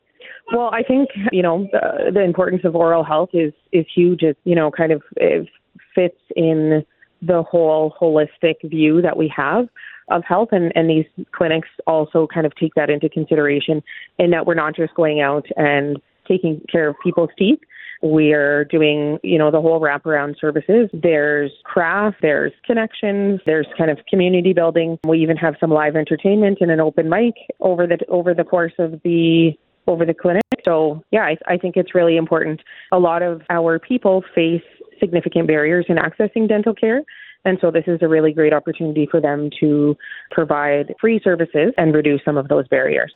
Alook speaks on the importance of dental care within the Metis communities, along with helping to build community and connections and providing wrap around services for dental to MNA citizens.